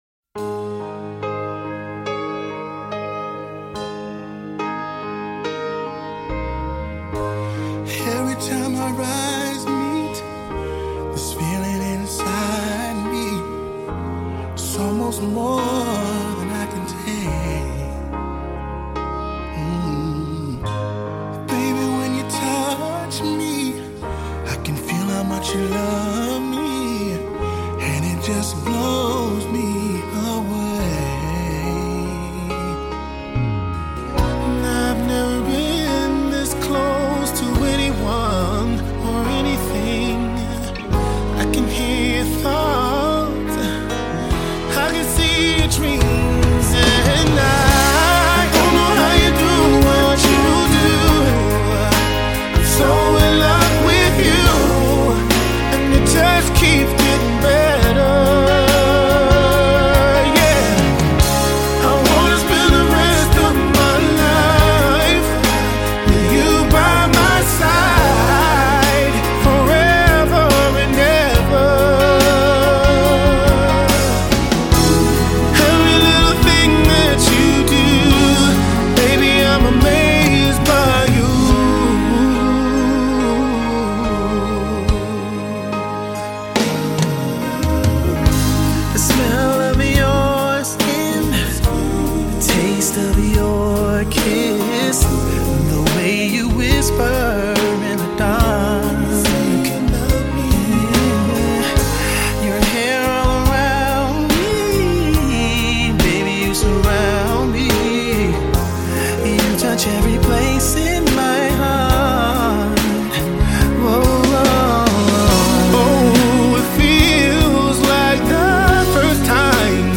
Blues Music